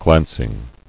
[glanc·ing]